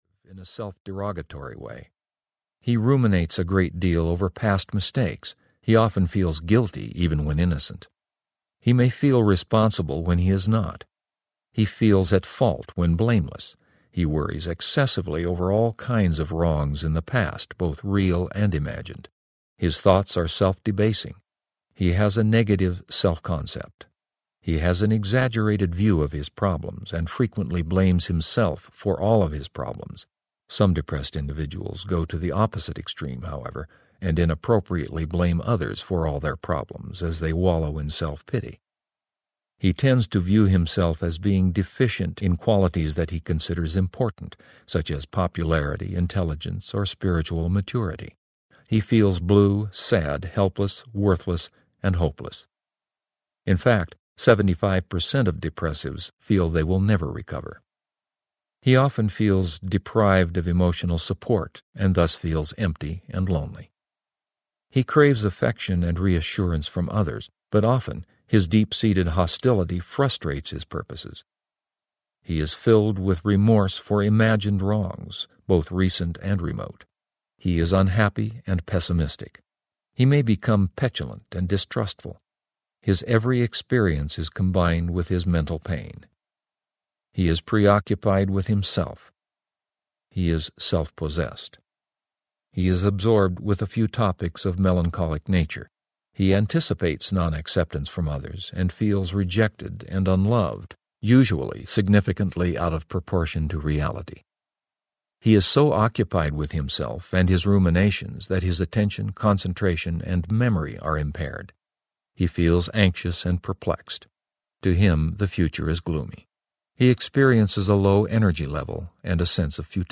Happiness Is a Choice Audiobook
7.2 Hrs. – Unabridged